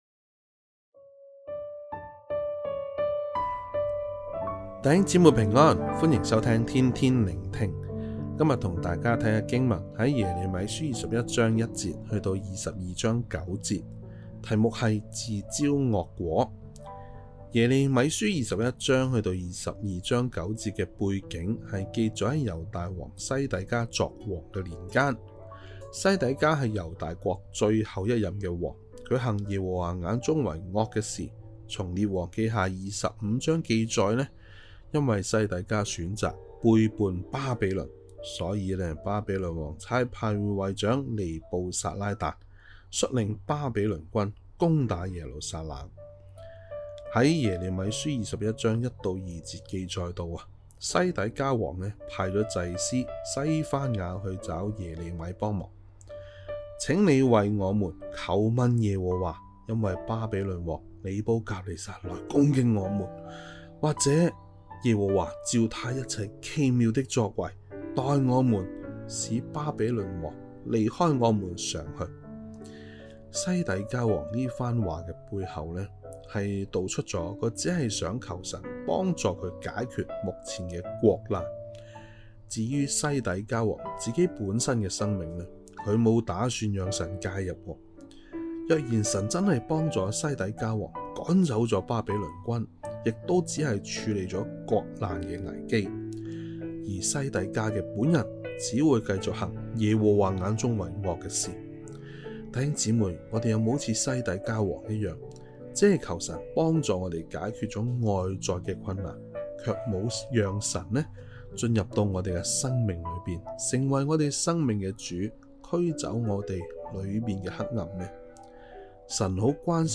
粵語錄音連結🔈